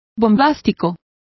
Complete with pronunciation of the translation of bombastic.